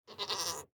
Minecraft Version Minecraft Version snapshot Latest Release | Latest Snapshot snapshot / assets / minecraft / sounds / mob / goat / idle6.ogg Compare With Compare With Latest Release | Latest Snapshot